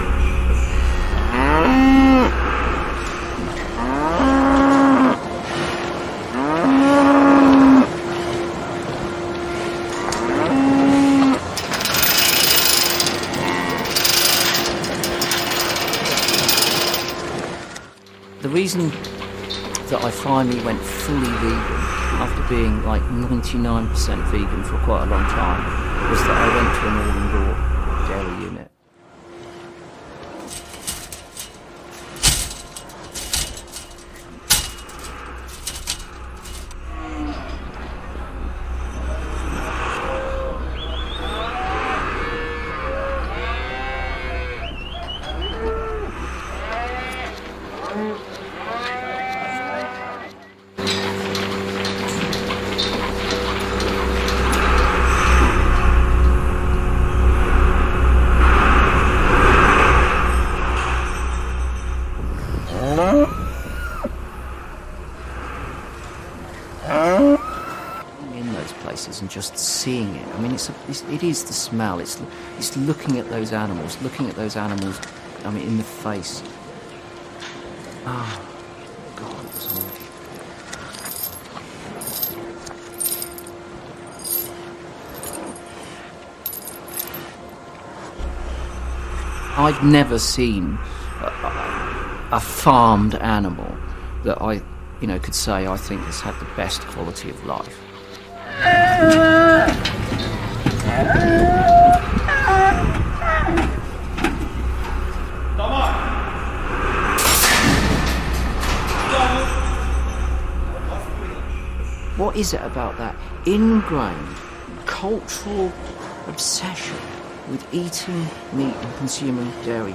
Our peaceful demo asked passersby to “please listen” while the haunting sounds of a dairy farm played out loud, including the monotonous sound of milking machines, hobbles (used to chain cows’ feet together) and the cries of a mother cow separated from her calf, mooing until her voice gives out...
We also included snippets of Animal Aid patron, Chris Packham, talking about his experience of visiting an all-indoor dairy unit – an experience that turned him fully vegan.